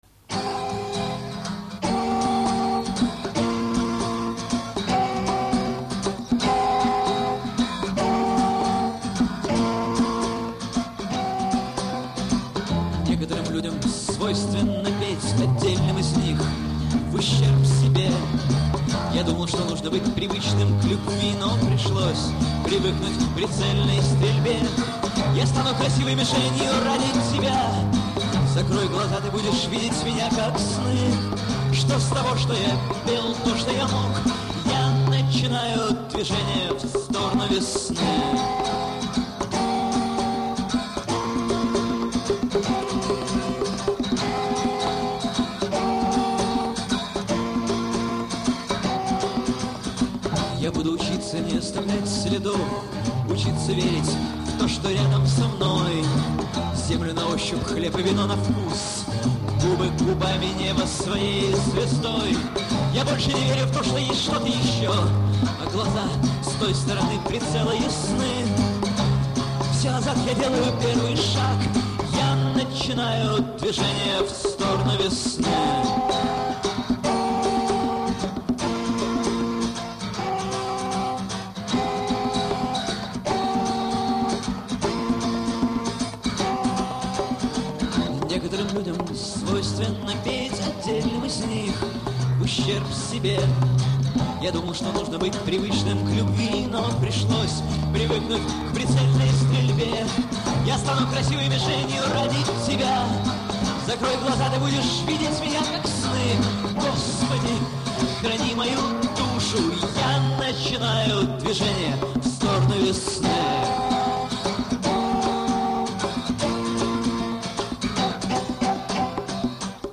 Акустический концерт